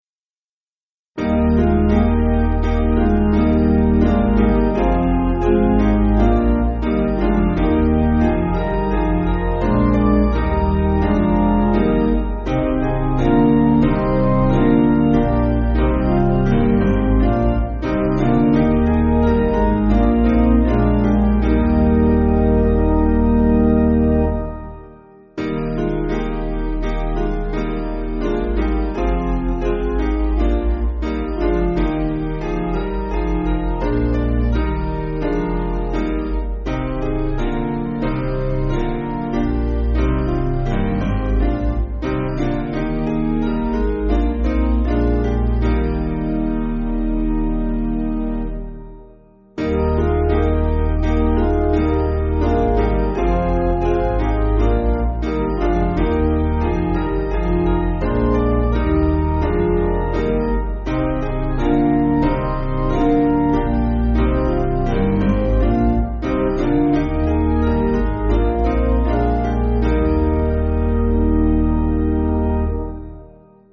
Basic Piano & Organ
(CM)   5/Eb